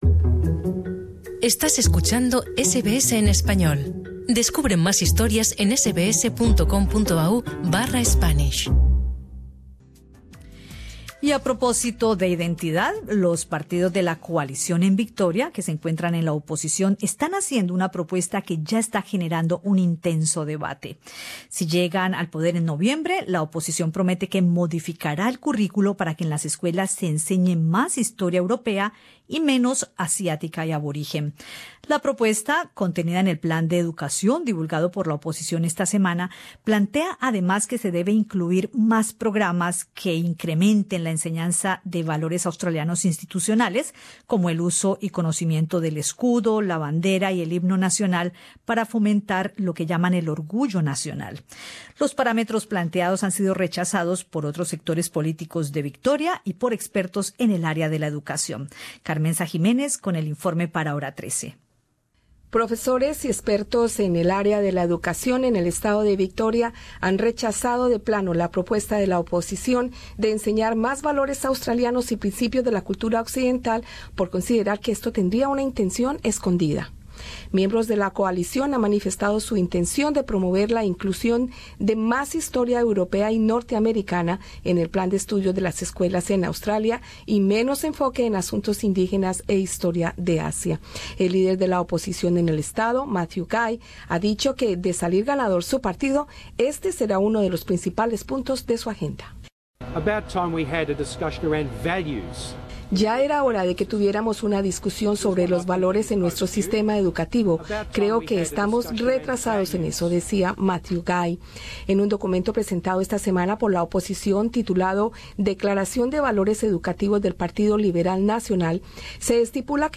Escucha arriba en nuestro podcast el informe con la opinion de profesores hispanos en Victoria.